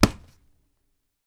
SLAP B    -S.WAV